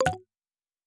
Buzz Error (10).wav